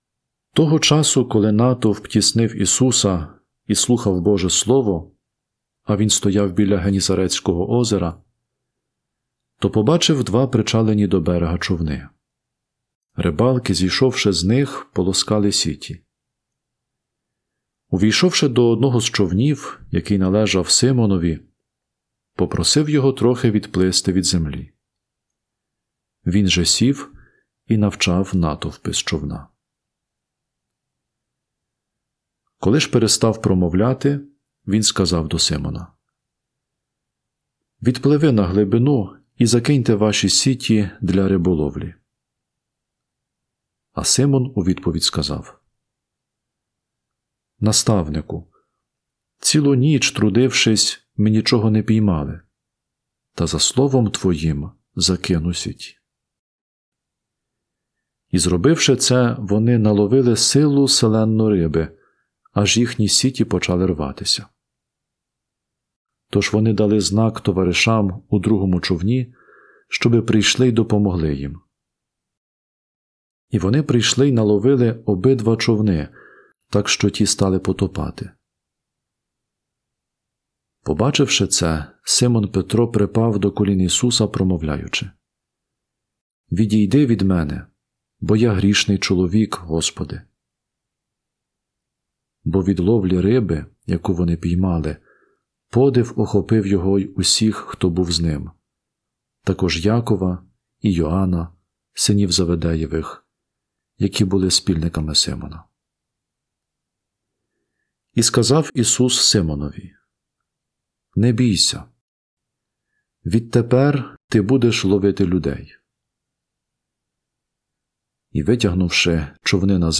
Євангеліє